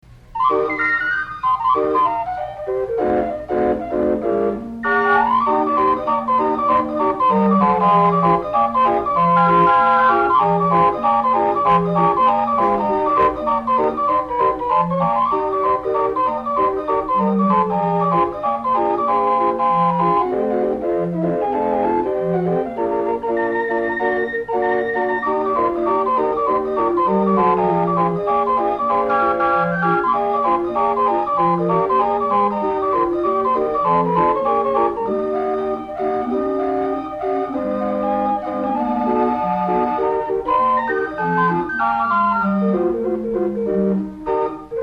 AUTHENTIC CIRCUS CALLIOPE MUSIC